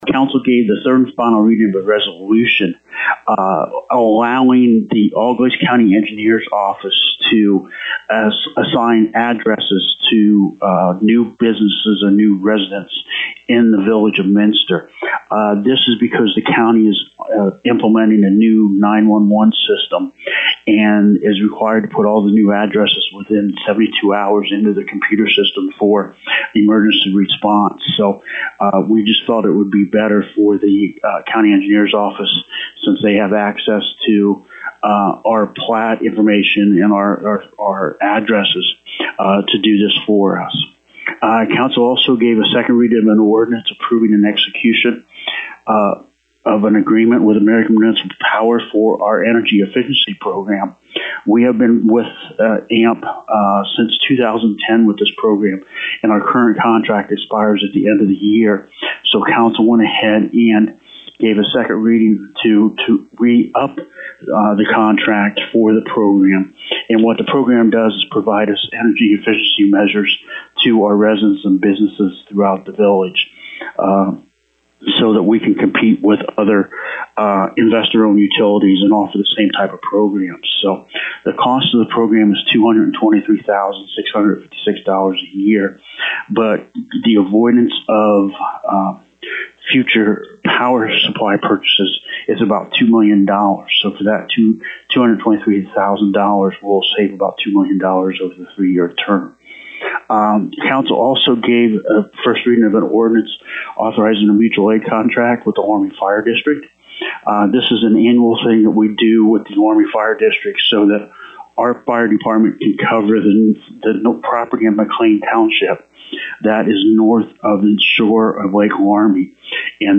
Local News